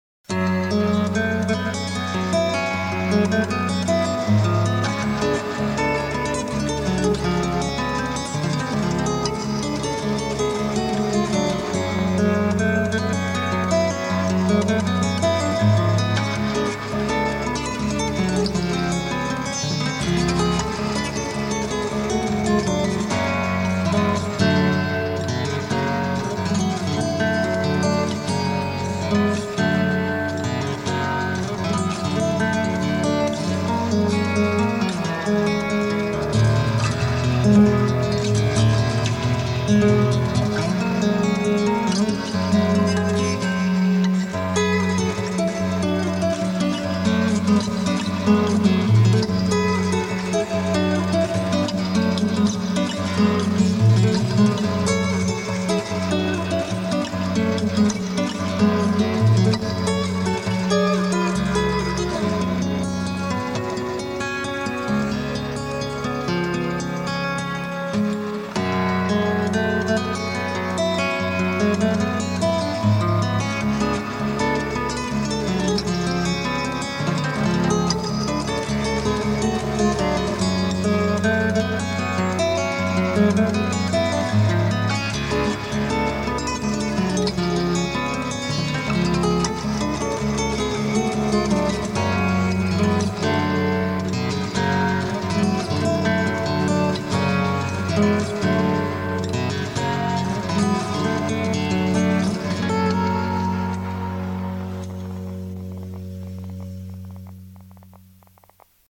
thrash
vocal, gitara
bębny
gitary